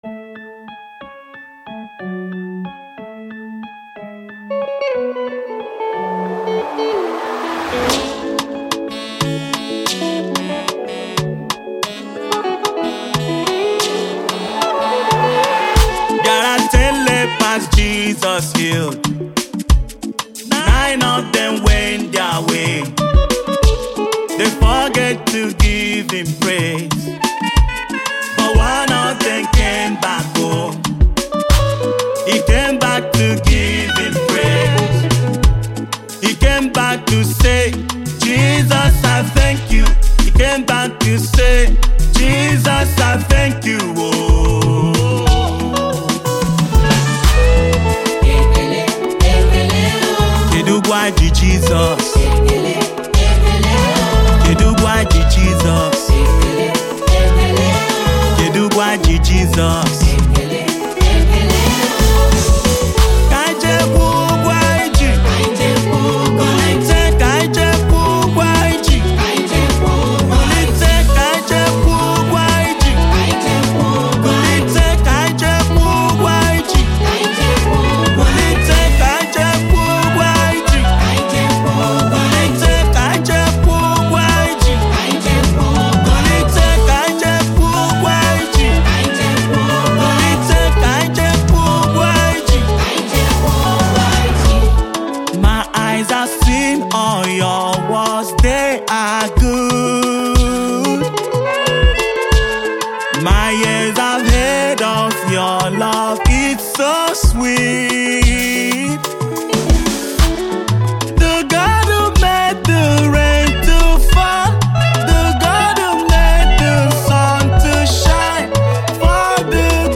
With infectious energy and a melodic flow